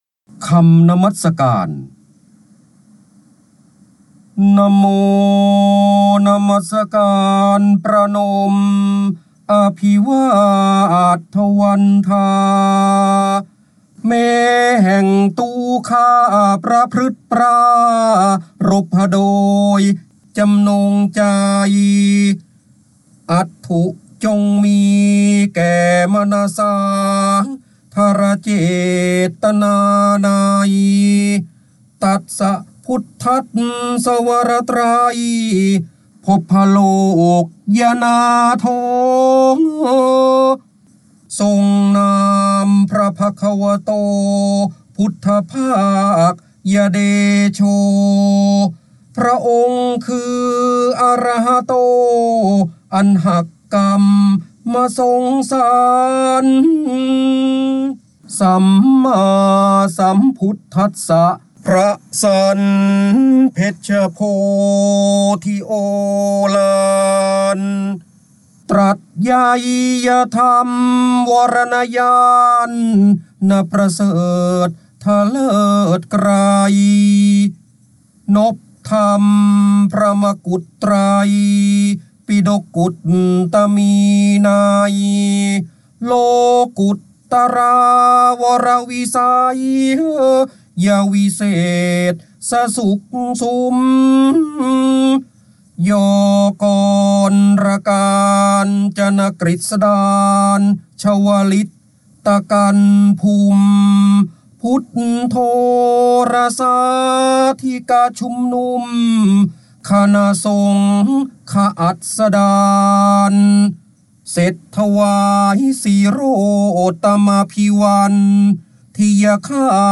เสียงบรรยายจากหนังสือ จินดามณี (พระโหราธิบดี) คำนมัสการ
คำสำคัญ : พระโหราธิบดี, ร้อยแก้ว, พระเจ้าบรมโกศ, จินดามณี, การอ่านออกเสียง, ร้อยกรอง